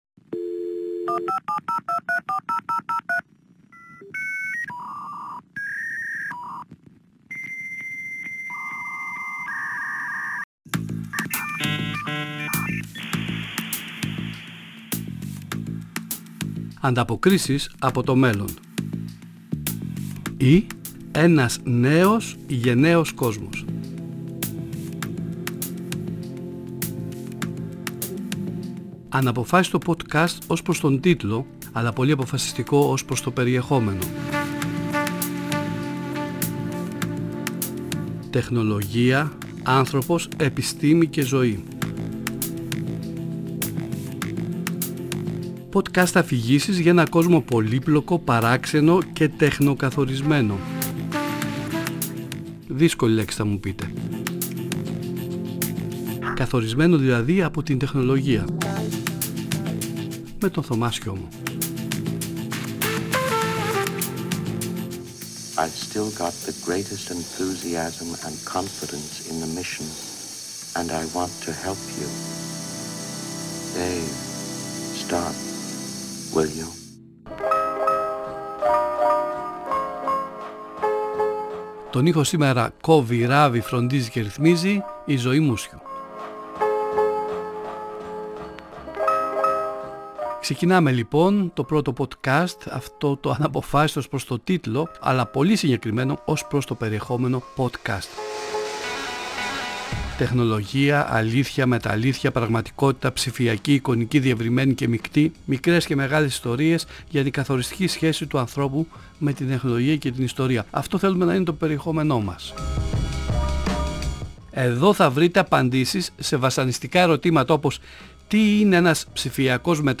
Μια σειρα podcast αφηγήσεων για τον τεχνοκαθορισμένο “πλανητικό χωριό”.